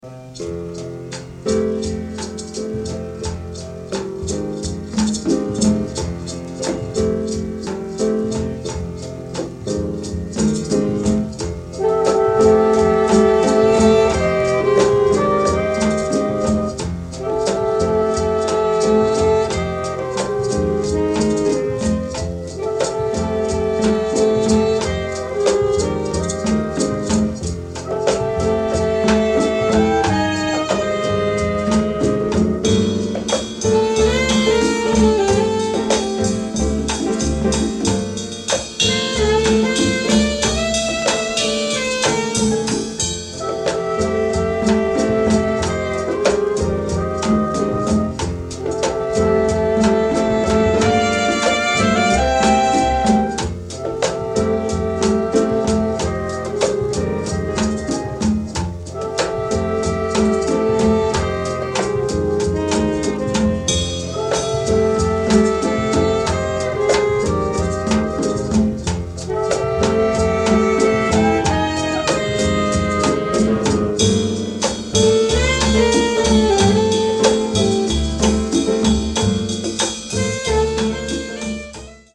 Ultimate jazz holy grail reissue.